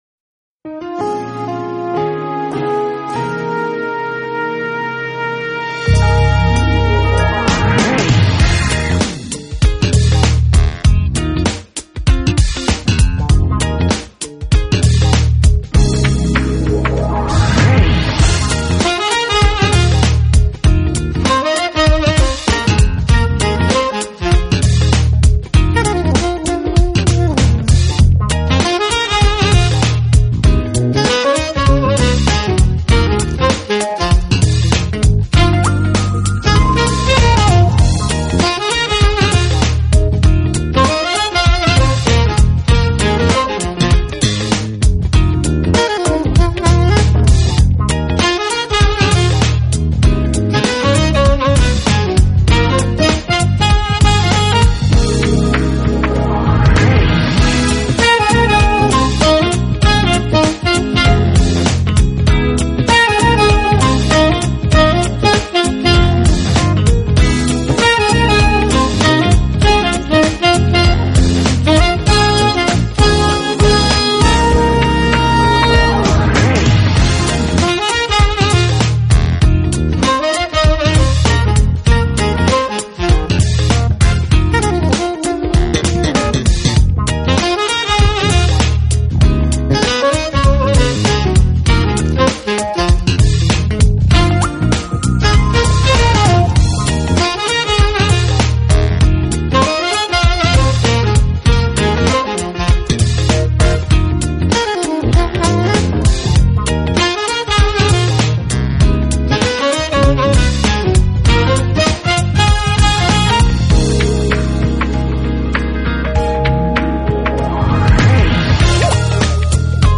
【爵士萨克斯】
Genre: Smooth Jazz